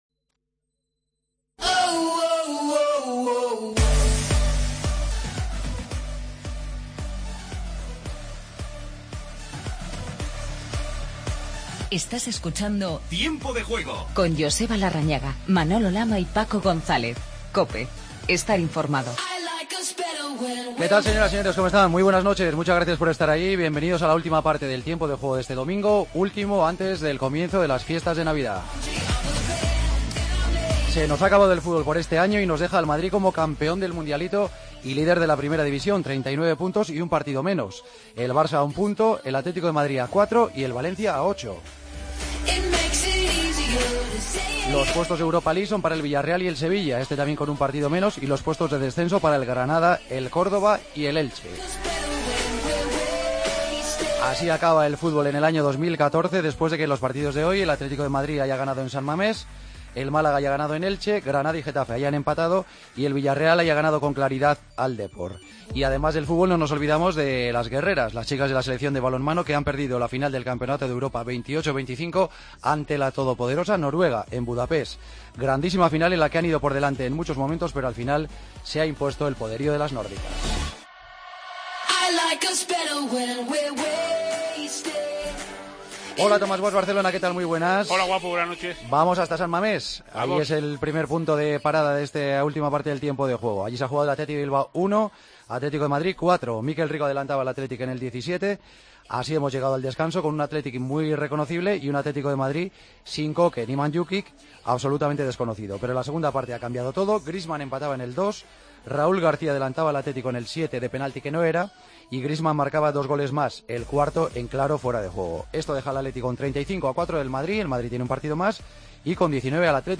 Hablamos con Carlos Gurpegui, capitán del Athletic, y Antoine Griezmann, jugador del Atlético de Madrid.